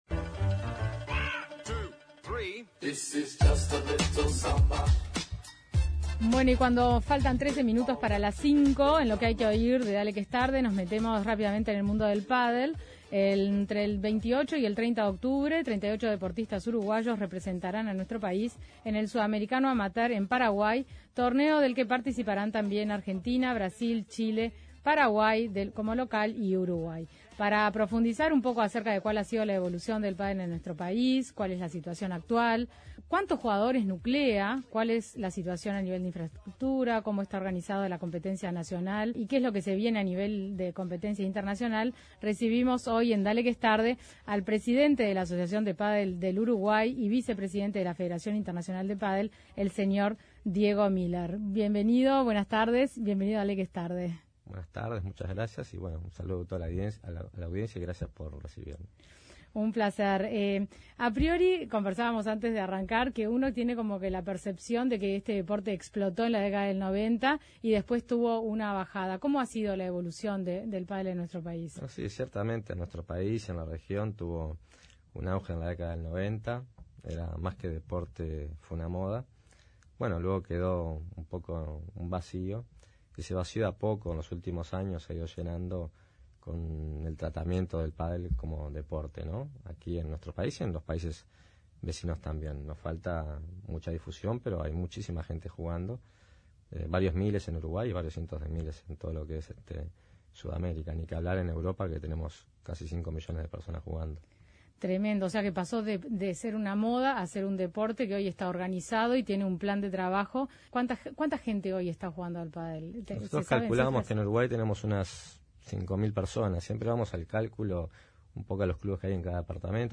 en diálogo